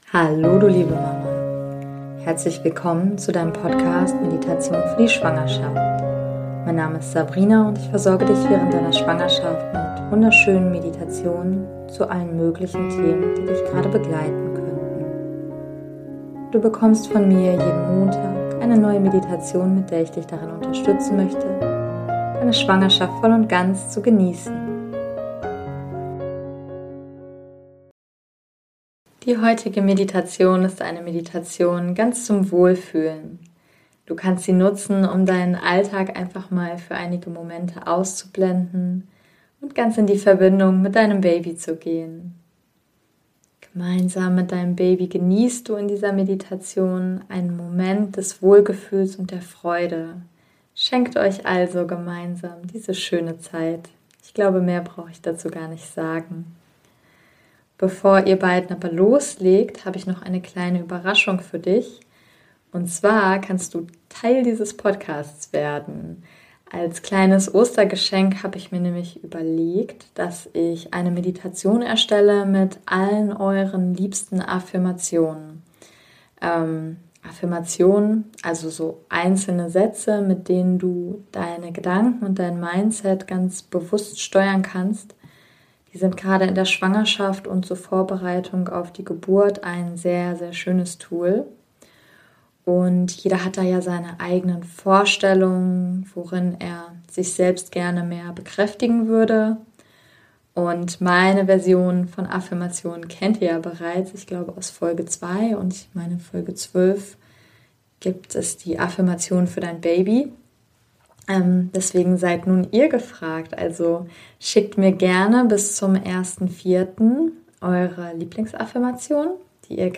#022 - Meditation zum Wohlfühlen in der Schwangerschaft ~ Meditationen für die Schwangerschaft und Geburt - mama.namaste Podcast